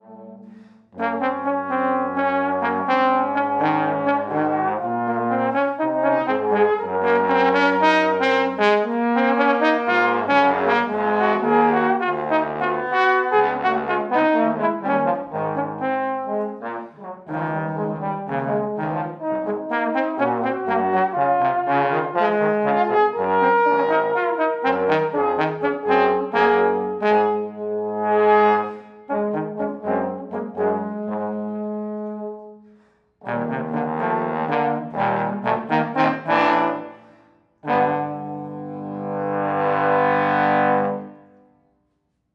Trombone Trio
two tenor trombones (one doubling alto) and bass trombone
The third movement is a dance-whatever-the-future-brings.